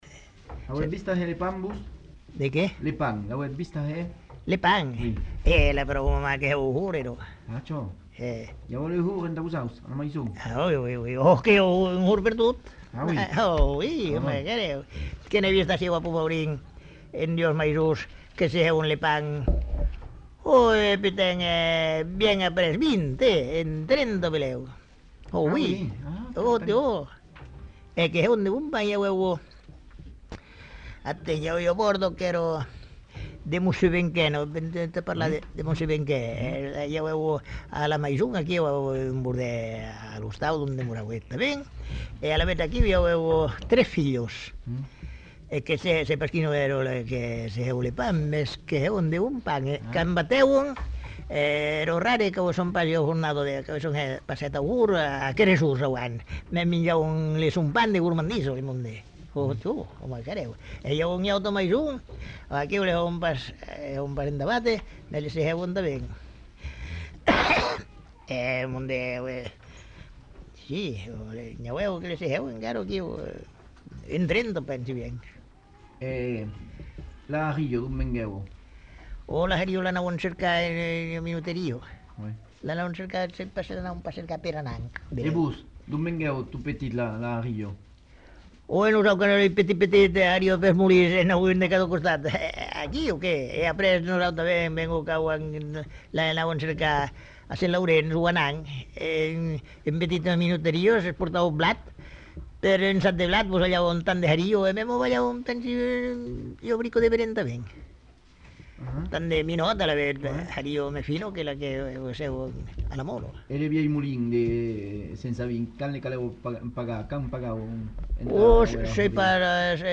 Lieu : Garravet
Genre : témoignage thématique